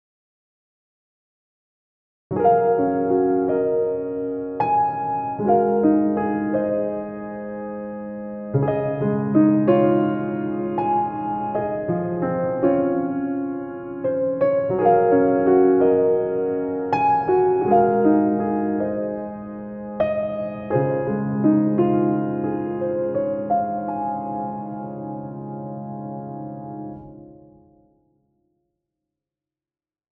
• Steinway B
• Sympathetic resonance cranked
• Long tail hall reverb
• soften hammers
• reduced dynamic range
• increased hammer noise
• condition set at about 25 (for tuning variation)